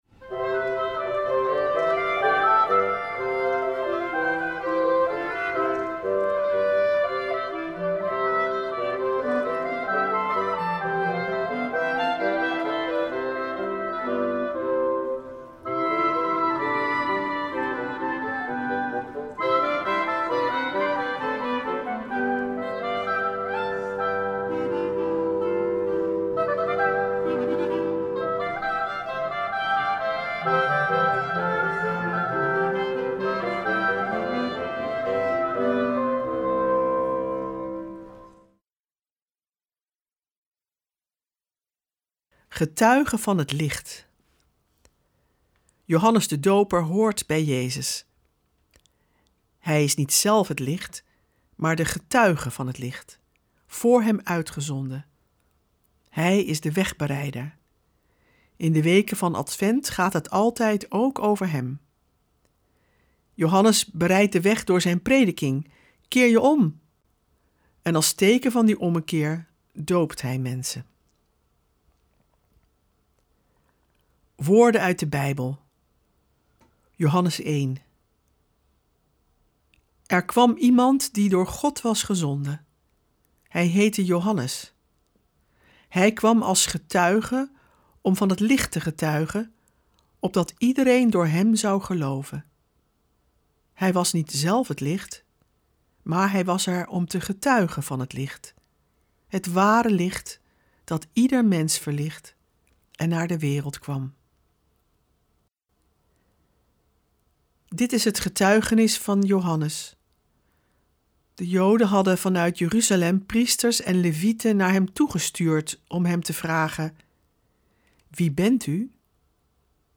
Ook dit jaar komen klassieke Bijbelteksten aan de orde, teksten die al eeuwen in de kerk juist in deze weken van Advent zijn gelezen. We verbinden ze met onze tijd en we luisteren naar prachtige muziek en poëzie.